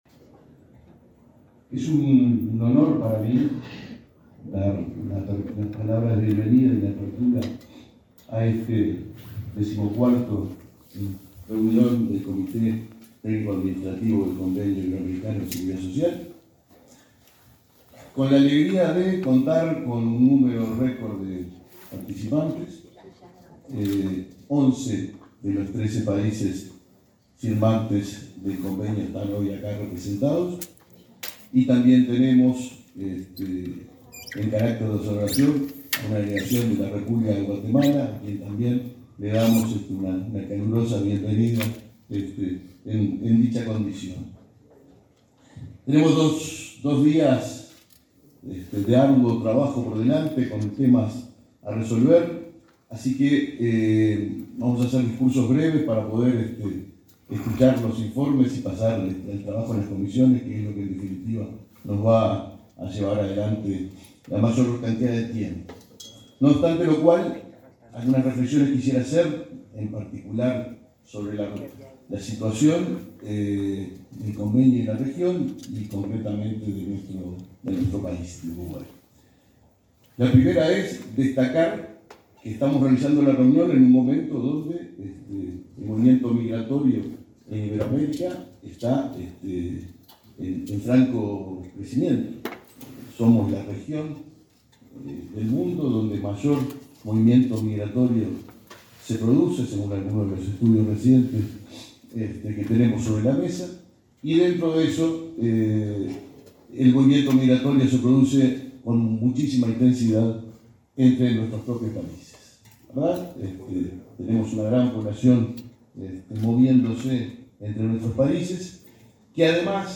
Palabras del presidente del BPS, Alfredo Cabrera
Palabras del presidente del BPS, Alfredo Cabrera 30/10/2023 Compartir Facebook X Copiar enlace WhatsApp LinkedIn El presidente del Banco de Previsión Social (BPS), Alfredo Cabrera, participó de la apertura de la XIV reunión del Comité Técnico Administrativo del Convenio Multilateral Iberoamericano de Seguridad Social, que se realiza este lunes 30 en Montevideo.